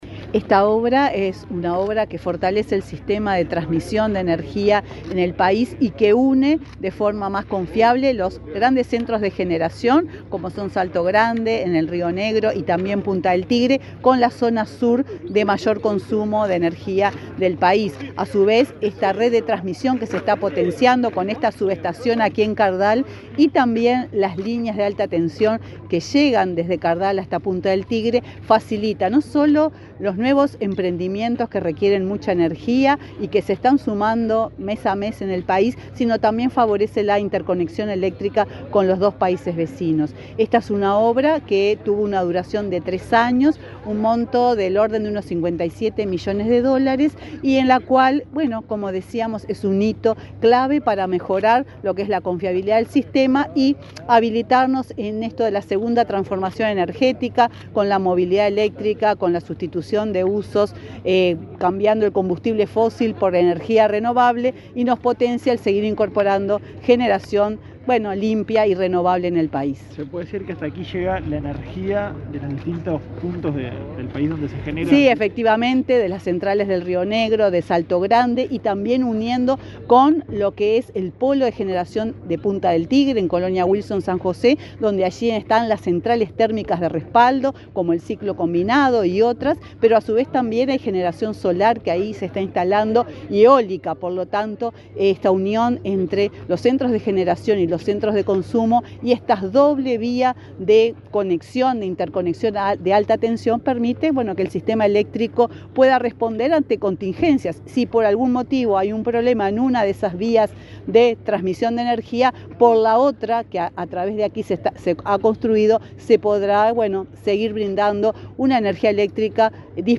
Declaraciones de la presidenta de UTE, Silvia Emaldi
Declaraciones de la presidenta de UTE, Silvia Emaldi 12/07/2024 Compartir Facebook X Copiar enlace WhatsApp LinkedIn La presidenta de UTE, Silvia Emaldi, efectuó declaraciones a medios informativos en Florida, antes de participar del acto de inauguración de la subestación de trasmisión Cardal de 500 kv y la línea extra alta tensión Punta del Tigre, en la localidad de Cardal.